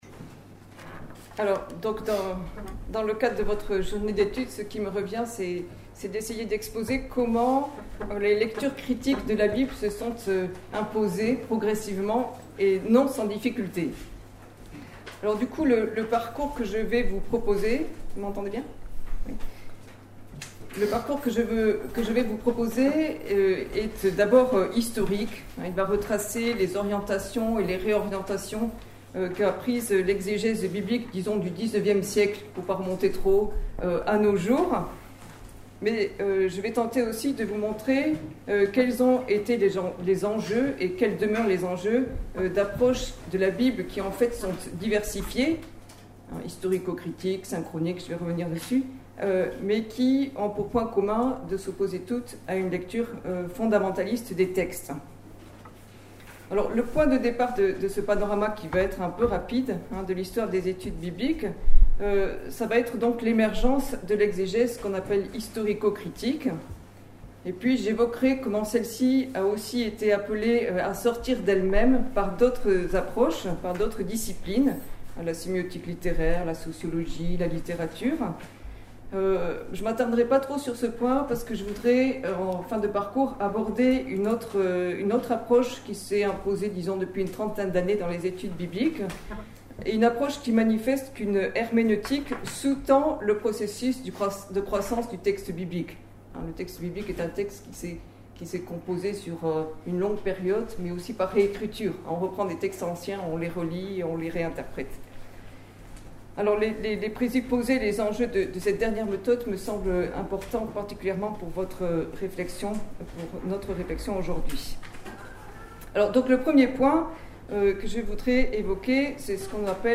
spécialiste en études bibliques Accédez à l'intégralité de la conférence en podcast audio dans l'onglet téléchargement